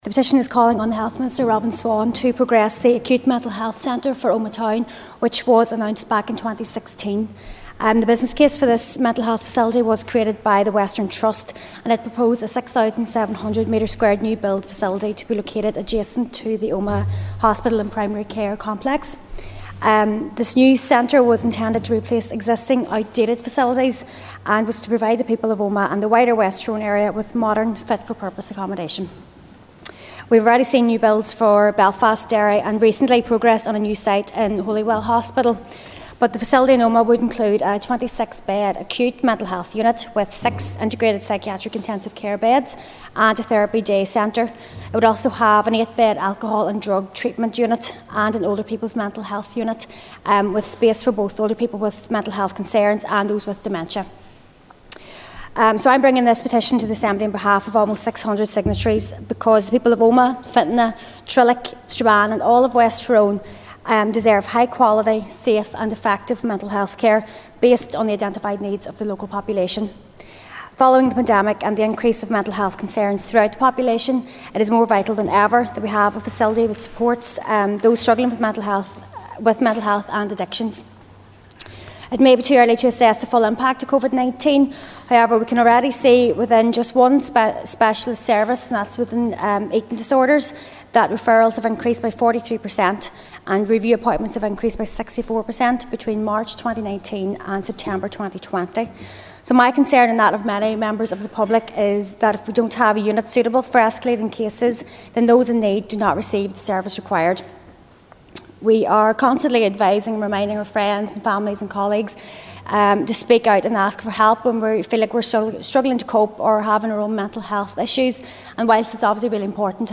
Presenting the petition on the floor of the Assembly at Stormont, Ms Brogan said this facility was first announced in 2016 on foot of a comprehensive business case.